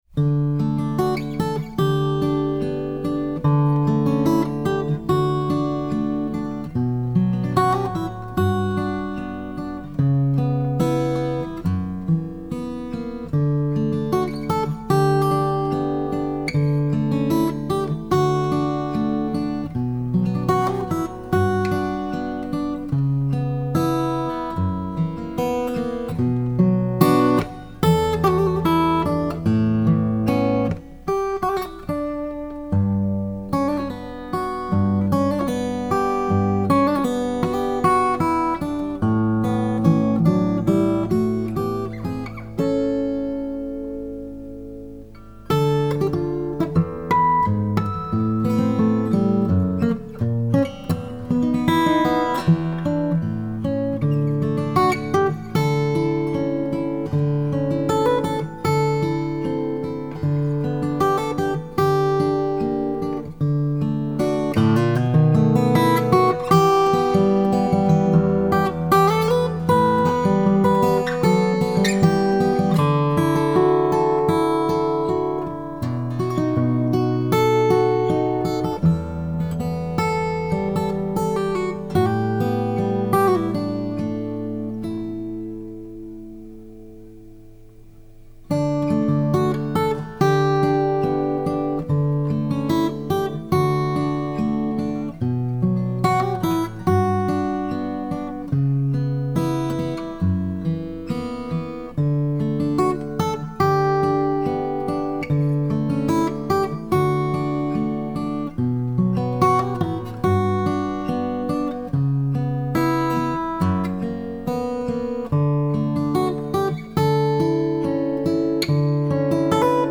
Вот запись, которую мой друг сделал с помощью моего SC (в подписи):
Маленькая прелюдия для любви в Ре мажор